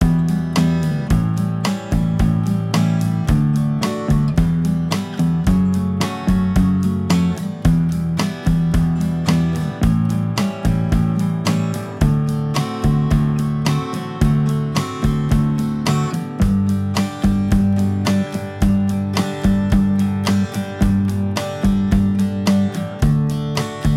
Minus Acoustic Guitar Pop (1960s) 2:19 Buy £1.50